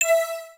UIClick_Mallet Tonal Long 01.wav